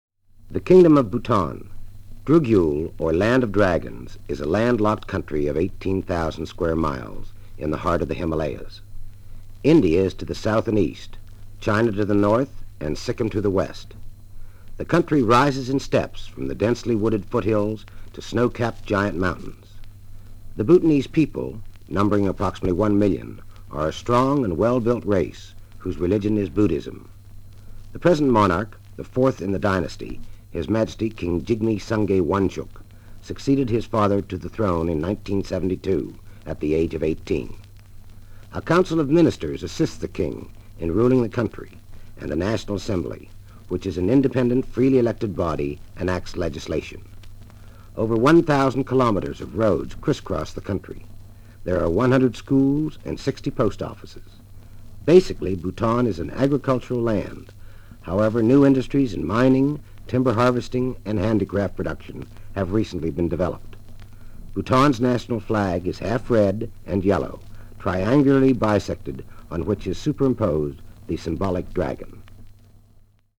These tiny records – possibly the smallest vinyl records that can still be played with a stylus – have an adhesive backside to affix to either a letter to postcard.